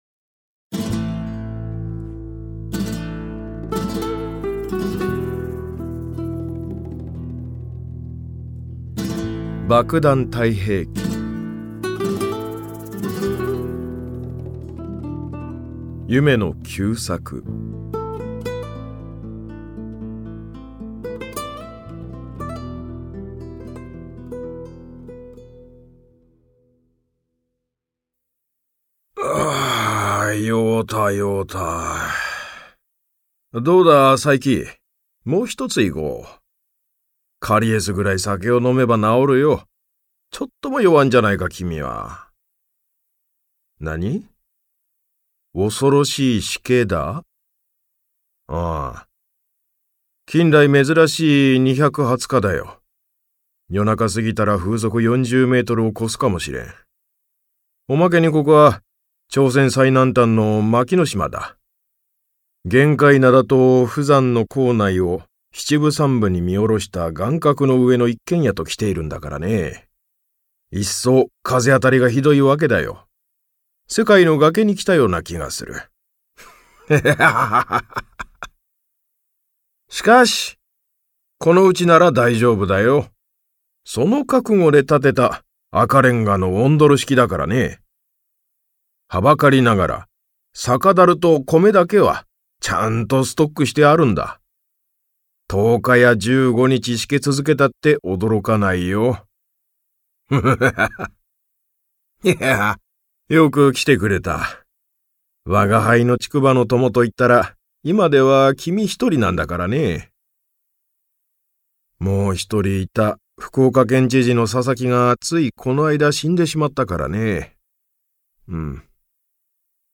[オーディオブック] 夢野久作「爆弾太平記」